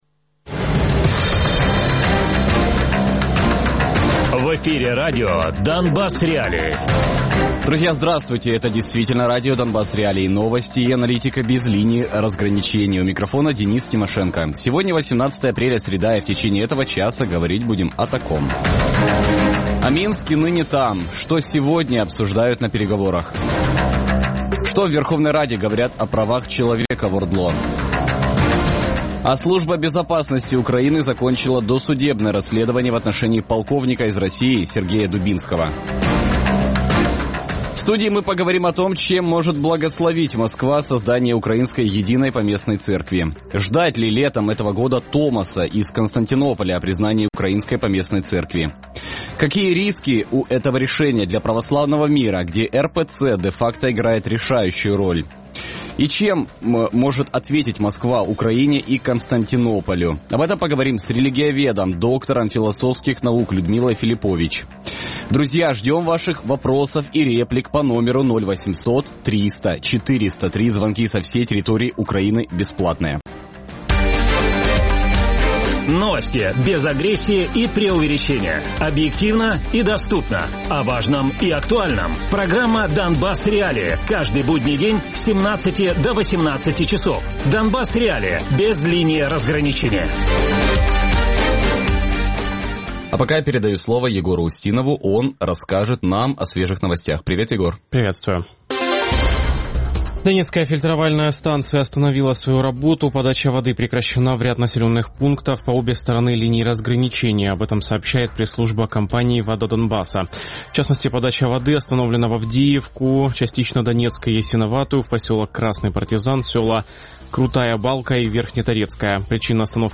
доктор філософських наук Радіопрограма «Донбас.Реалії» - у будні з 17:00 до 18:00.